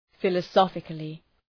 Προφορά
{,fılə’sɒfıklı}